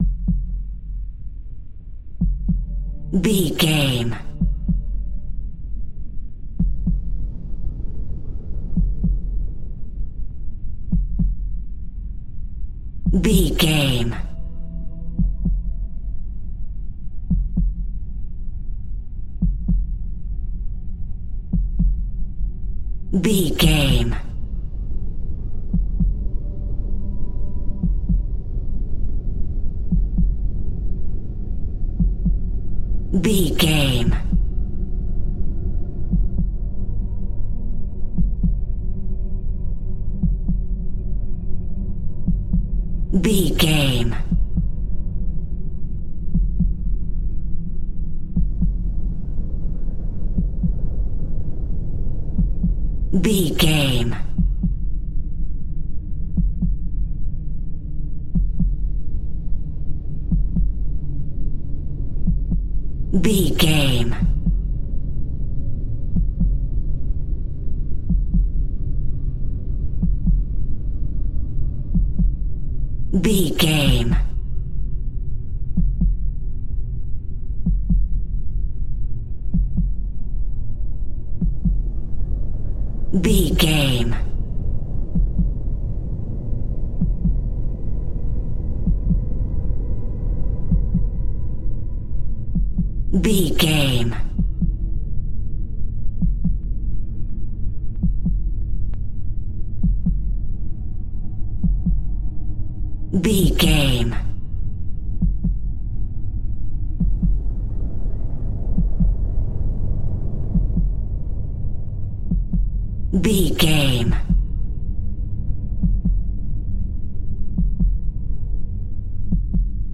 In-crescendo
Thriller
Atonal
scary
ominous
dark
eerie
synth
pads
eletronic